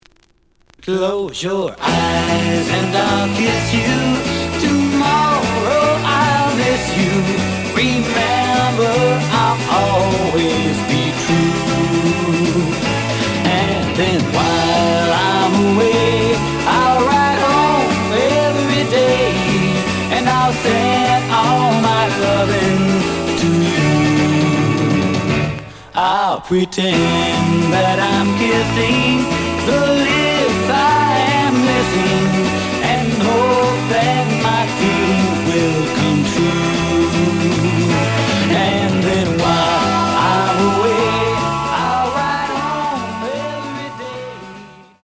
The audio sample is recorded from the actual item.
●Format: 7 inch
●Genre: Rock / Pop